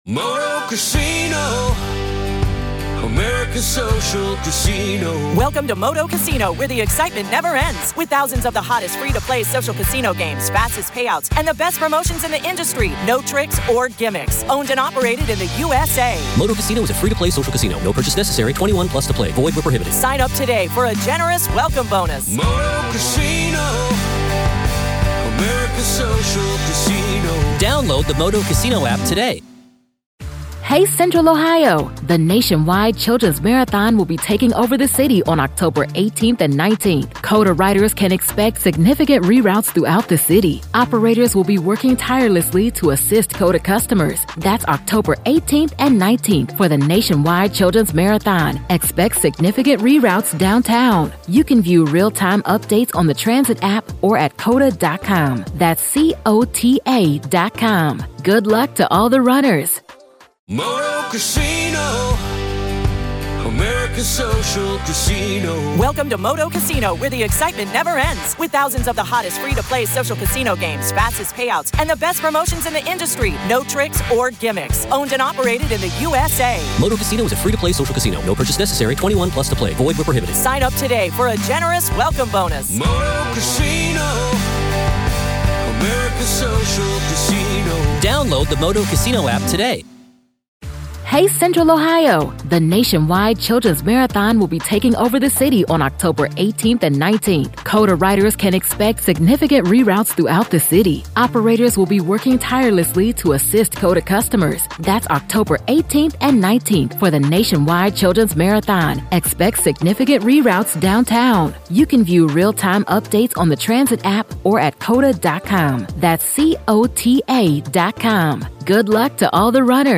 In this extended, voice-driven narrative